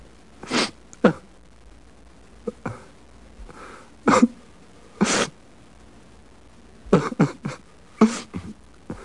Crying Male (short) Sound Effect
Download a high-quality crying male (short) sound effect.
crying-male-short.mp3